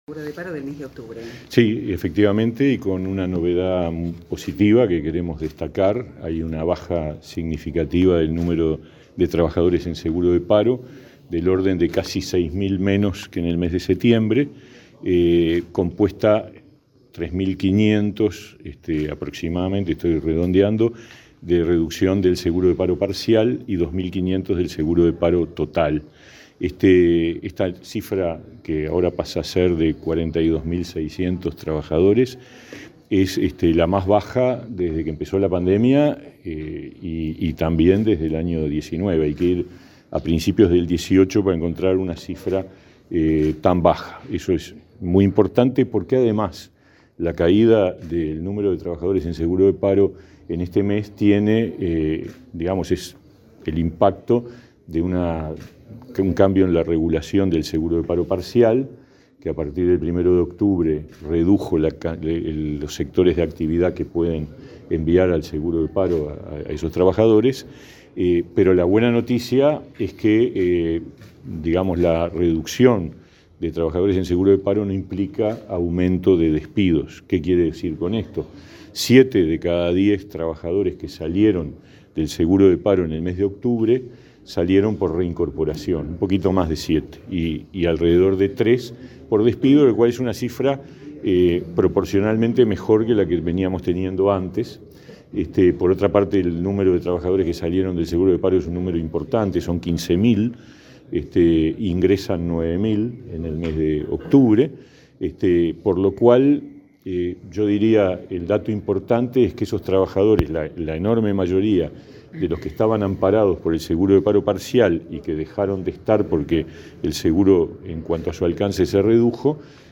Declaraciones del ministro de Trabajo, Pablo Mieres